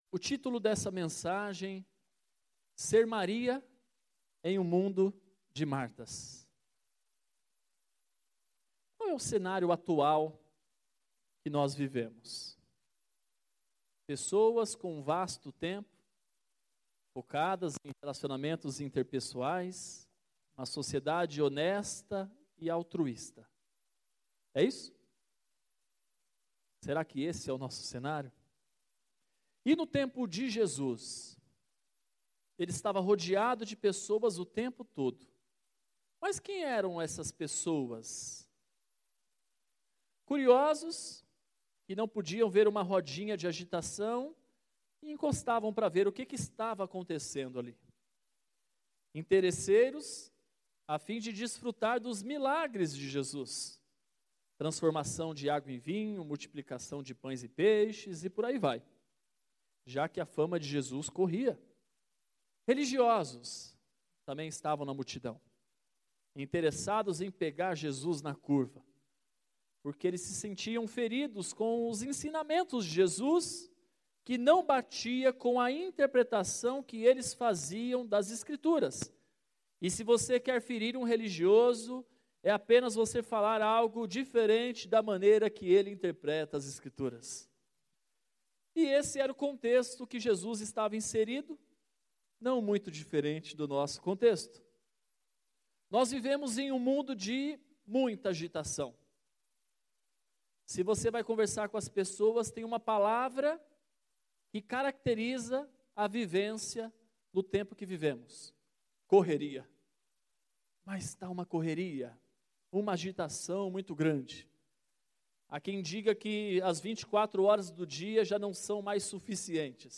Mensagem do dia 11 de Agosto de 2019.